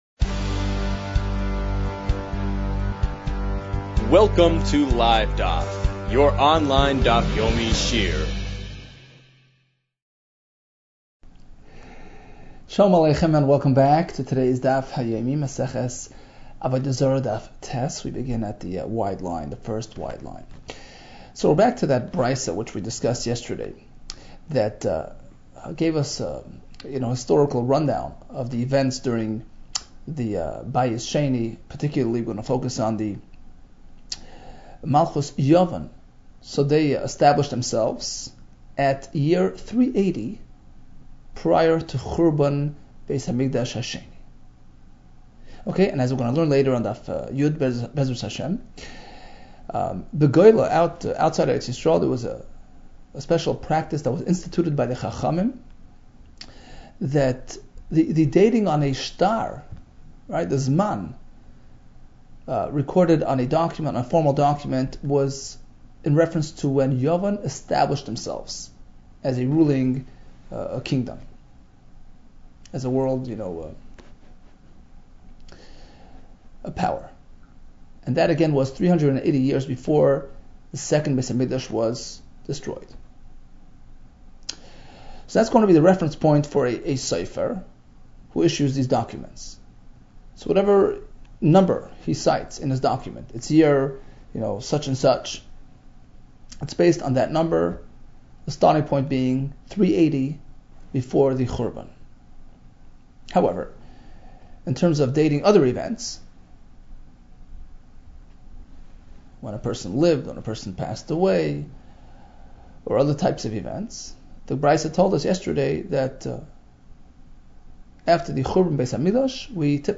Avodah Zarah 8 - עבודה זרה ח | Daf Yomi Online Shiur | Livedaf